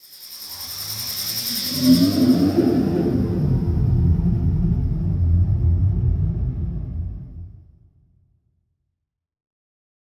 Index of /90_sSampleCDs/Best Service ProSamples vol.36 - Chillout [AIFF, EXS24, HALion, WAV] 1CD/PS-36 WAV Chillout/WAV Synth FX 1